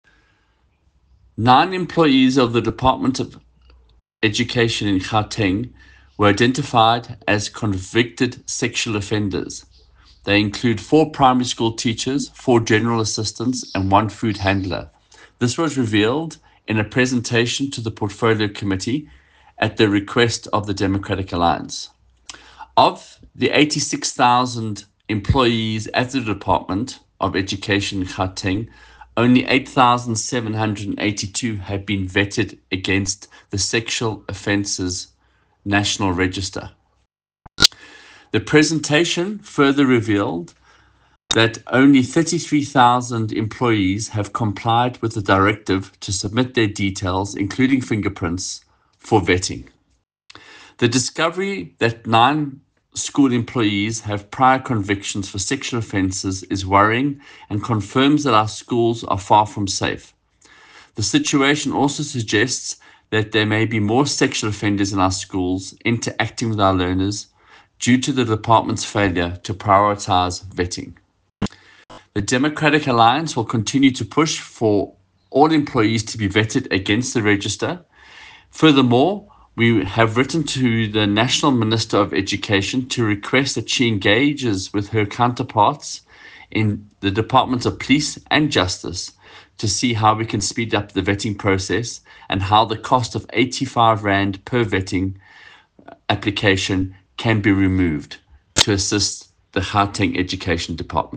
Note to Editors: Please find an English soundbite from DA MPL Michael Waters